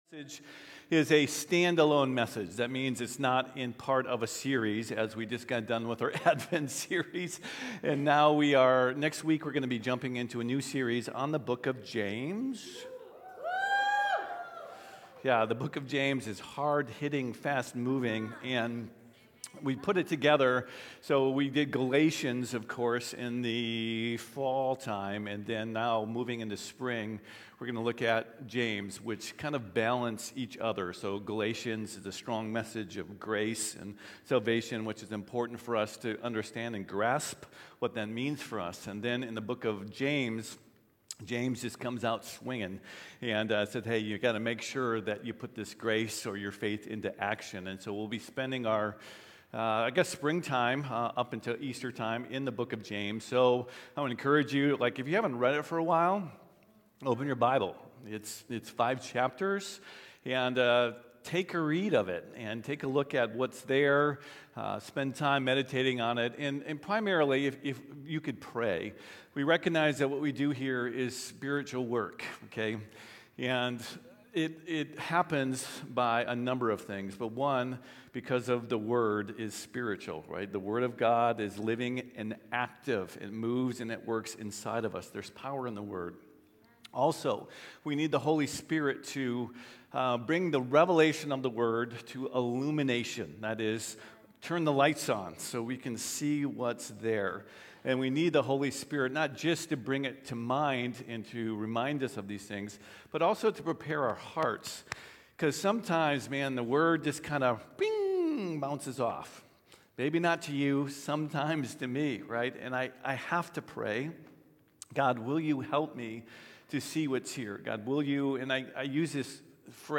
Crosspoint